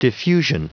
Prononciation du mot diffusion en anglais (fichier audio)
Prononciation du mot : diffusion